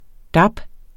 Udtale [ ˈdɑb ]